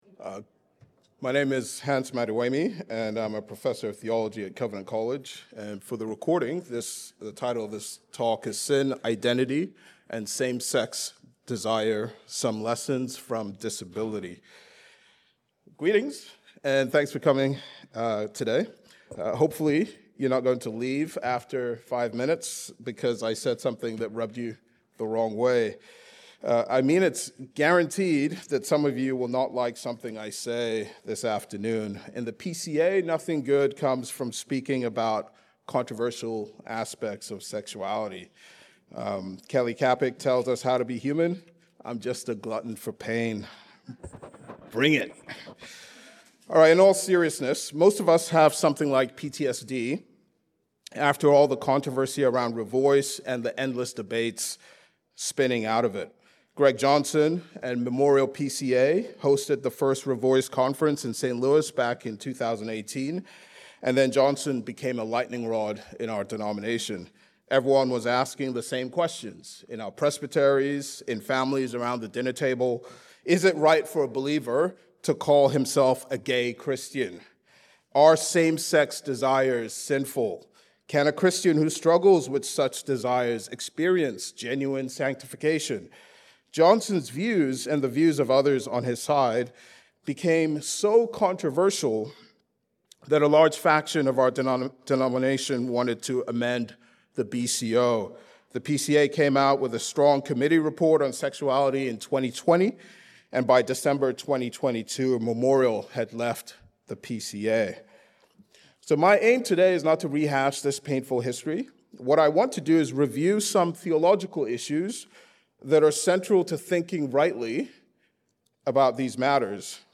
Covenant College The context of this seminar is ongoing debates about same-sex desire in light of the biblical story. To gain clarity, this seminar will interact with an intriguing dialogue among disability theologians about the resurrection.